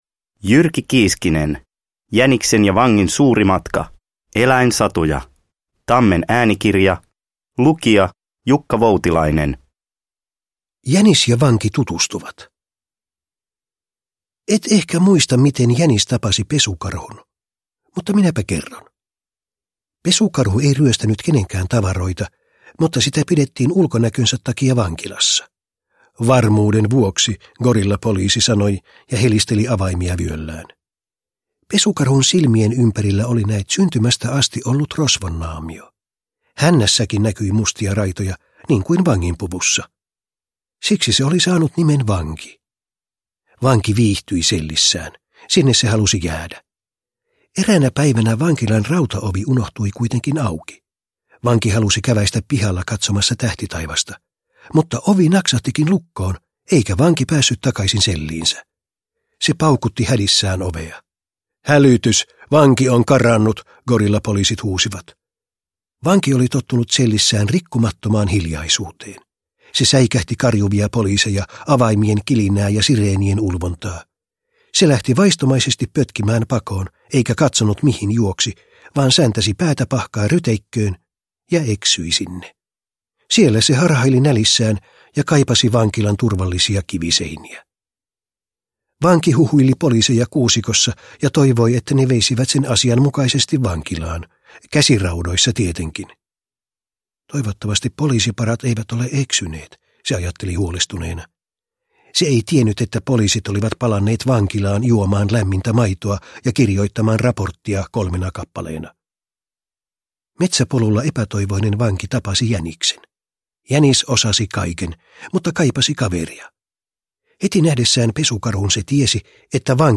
Jäniksen ja Vangin suuri matka – Ljudbok – Laddas ner